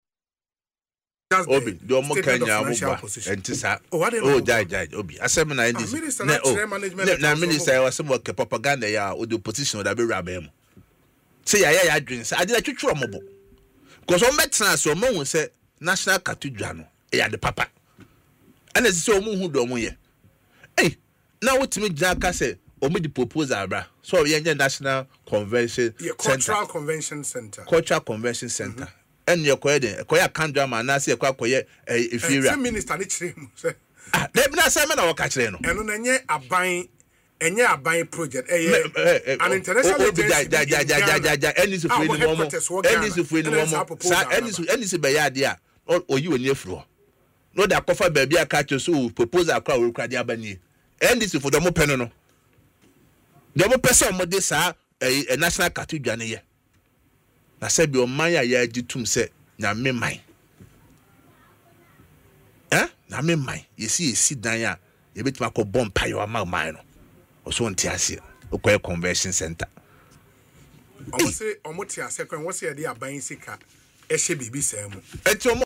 But in an interview on Asempa FM’s Ekosii Sen, Mr. Kodua dismissed the explanation, accusing the government of engaging in propaganda.